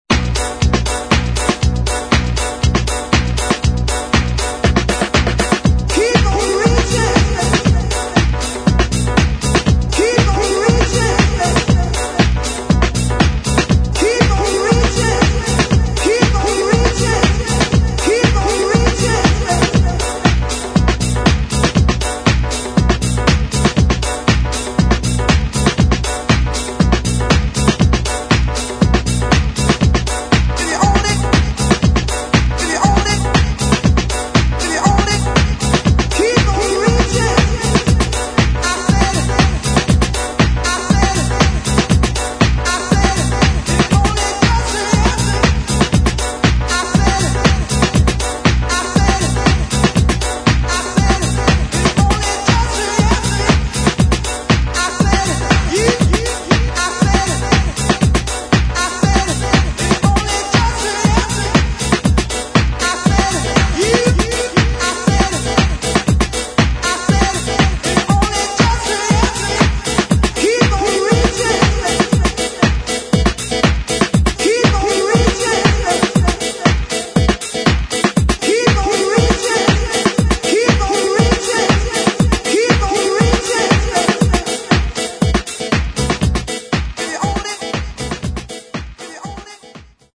[ DEEP HOUSE | TECHNO ]